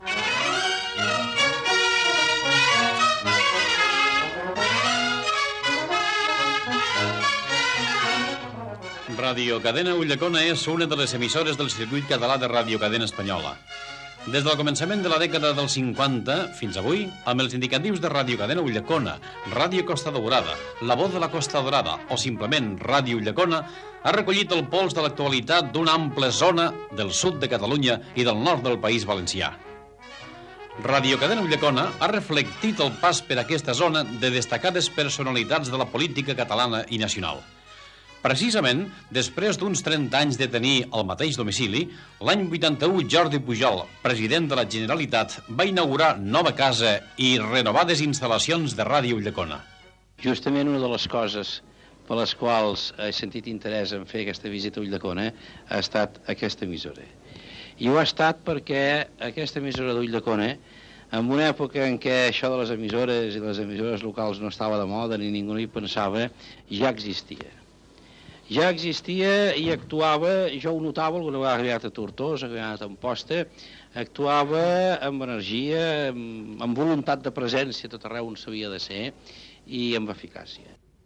Paraules del president de la Generalitat Jordi Pujol el dia de la inauguració dels nous estudis, a l'any 1981
Divulgació